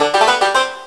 banjo.wav